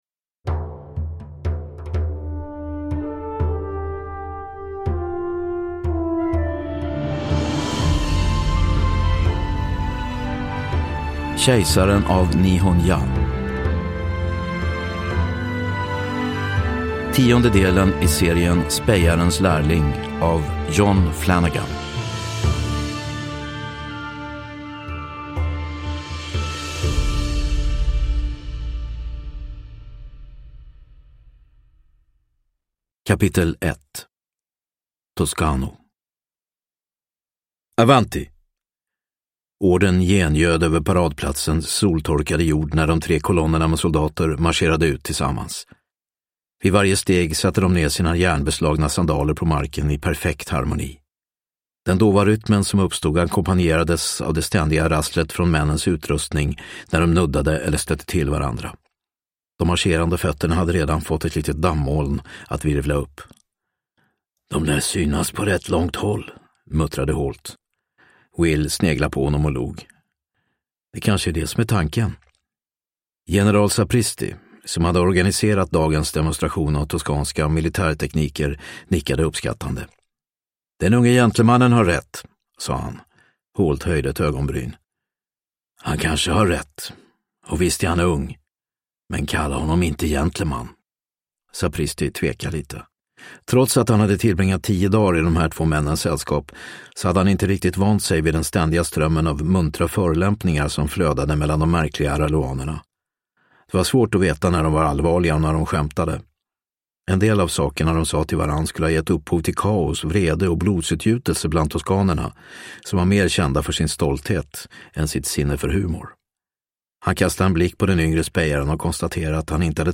Kejsaren av Nihon-Ja – Ljudbok – Laddas ner